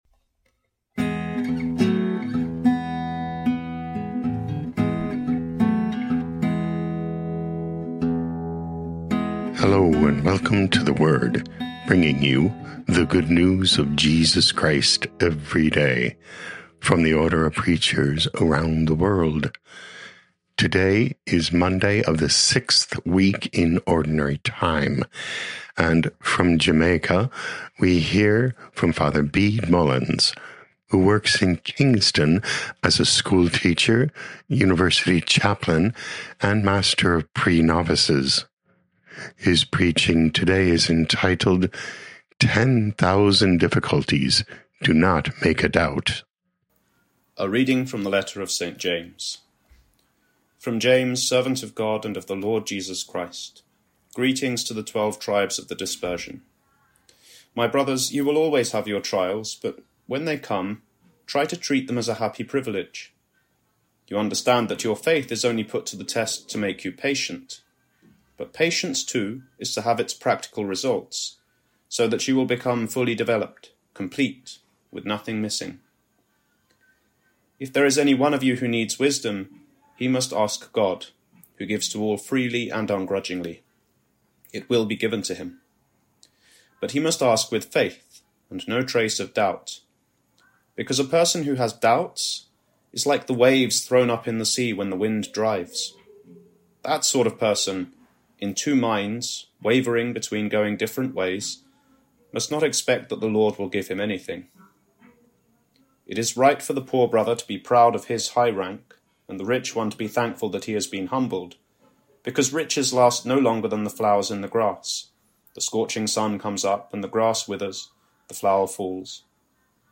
16 Feb 2026 Ten Thousand Difficulties do not Make a Doubt Podcast: Play in new window | Download For 16 February 2026, Monday of week 6 in Ordinary Time, based on James 1:1-11, sent in from Kingston, Jamaica.